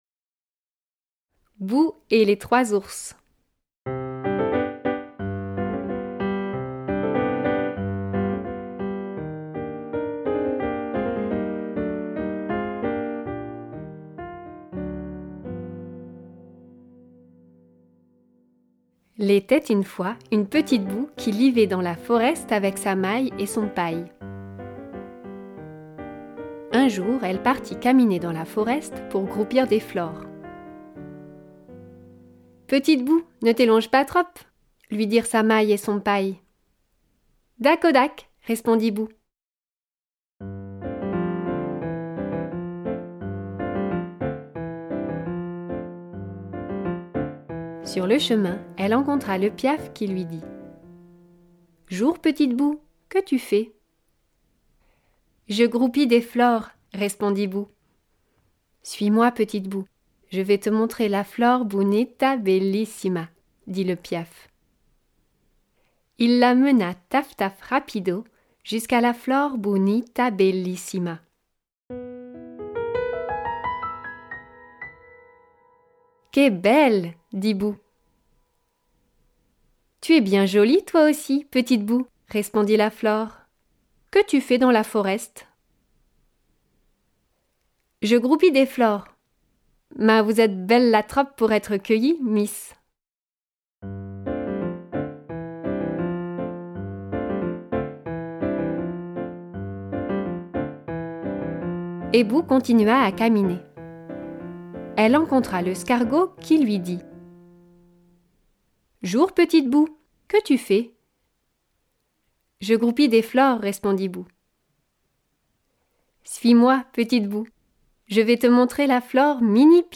25/10/2017 Lecture mise en musique de trois albums parus à L’Atelier du Poisson Soluble, dès 5 ans.